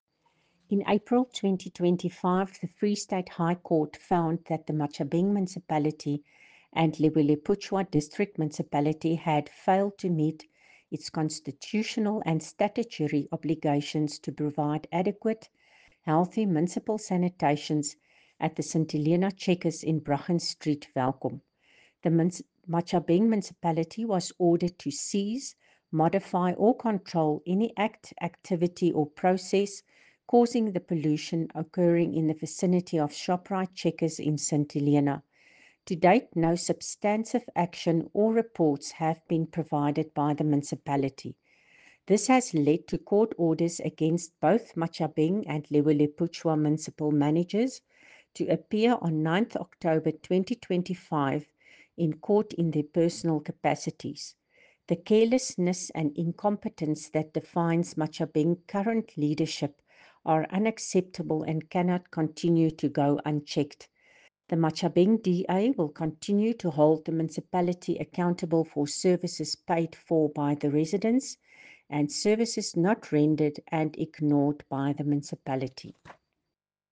Afrikaans soundbites by Cllr Coreen Malherbe and